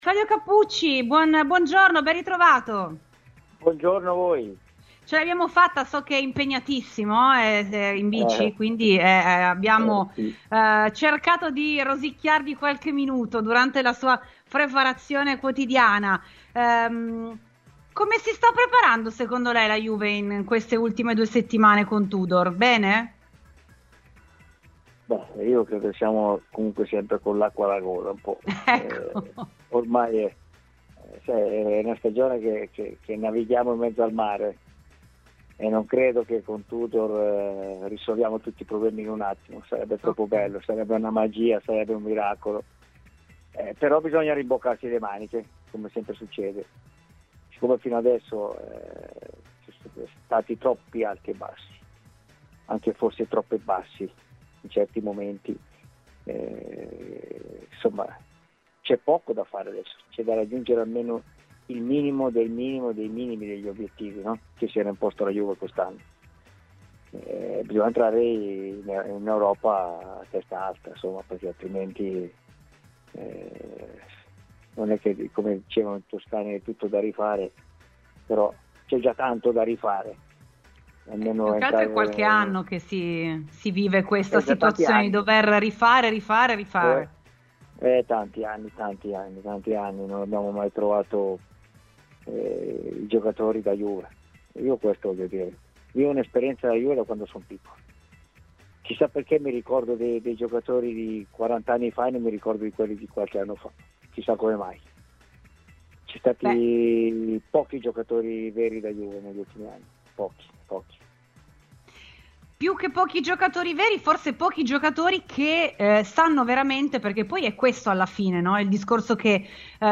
Ad esprimere tutto il suo disappunto parlando di un'annata storta è stato Claudio Chiappucci, ciclista e tifoso bianconero ai microfoni di RBN Cafè su Radiobianconera.